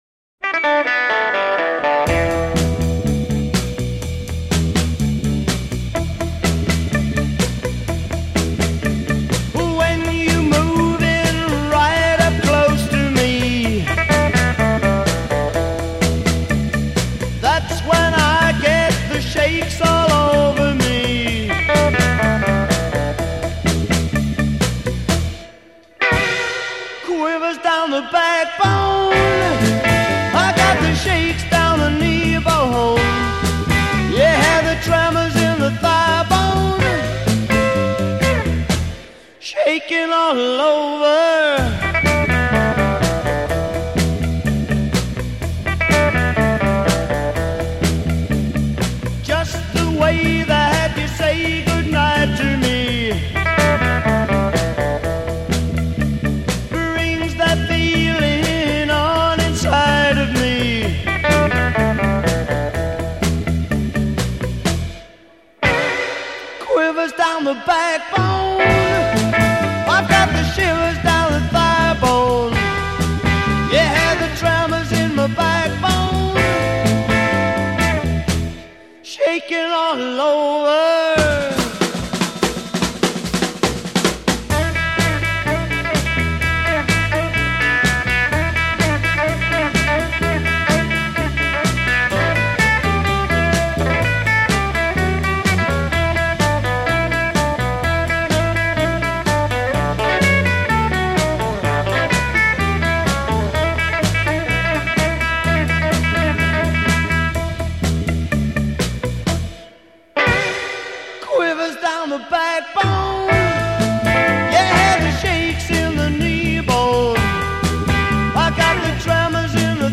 C refrain : 15 vocal with bent guitar responses b
segue   4 drum fill (repeated threes)